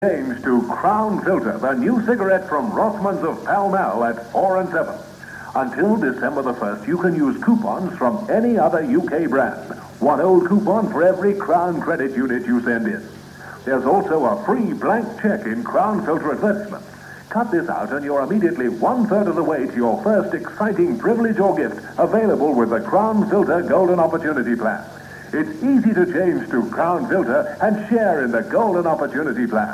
Rothmans Crown Filter advert.mp3